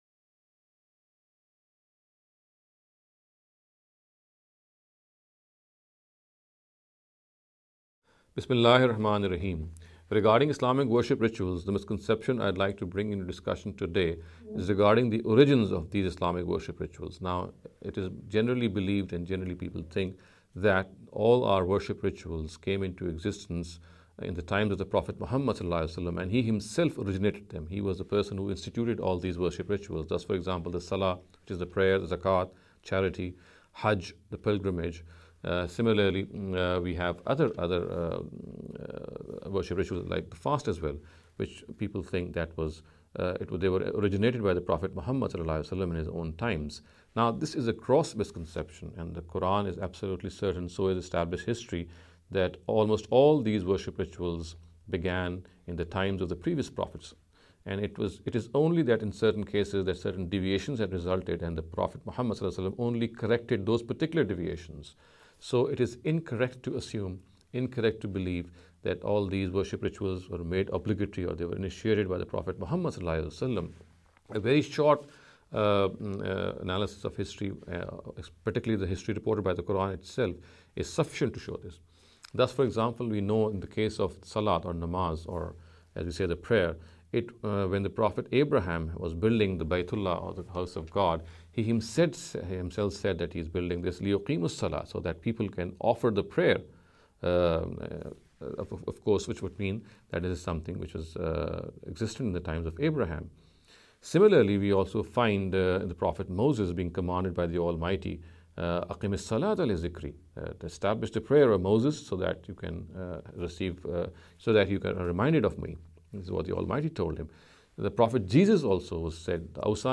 This lecture series will deal with some misconception regarding Islamic worship rituals.